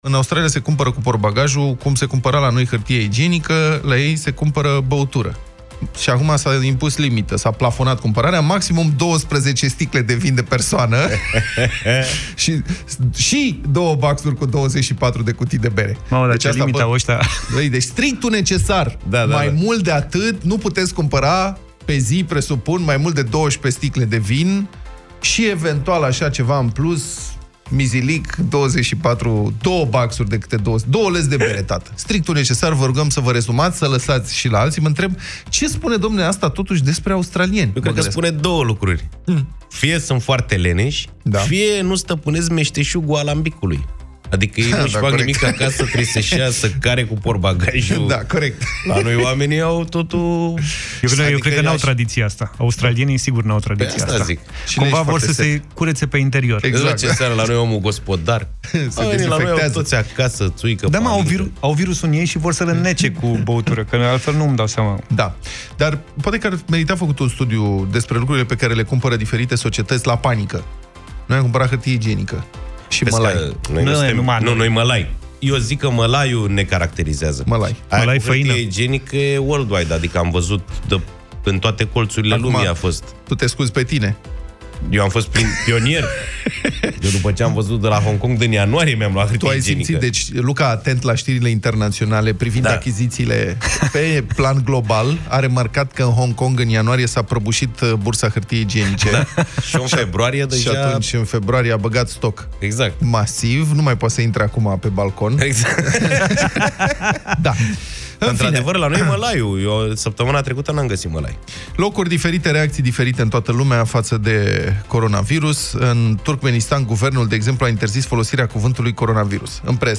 Puteți reasculta Deșteptarea din secțiunea podcast Europa FM, cu ajutorul aplicației gratuite pentru Android și IOS Europa FM sau direct în Spotify și iTunes.